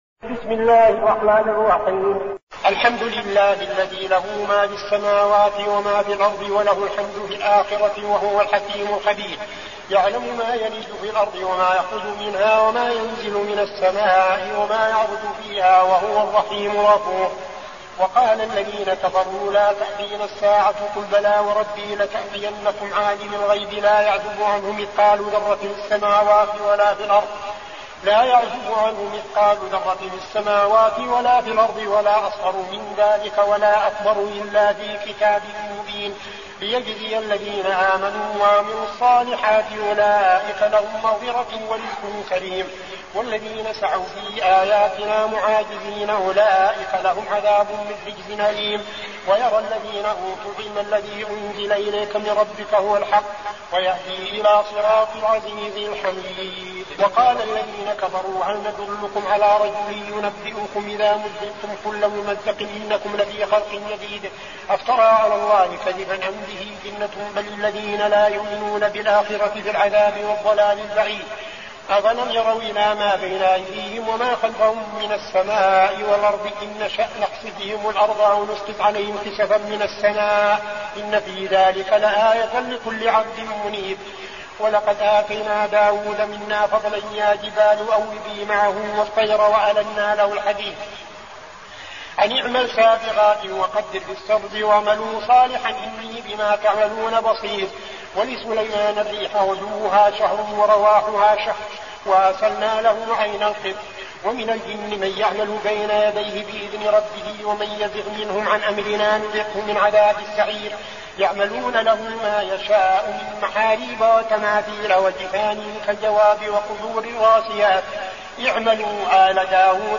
المكان: المسجد النبوي الشيخ: فضيلة الشيخ عبدالعزيز بن صالح فضيلة الشيخ عبدالعزيز بن صالح سبأ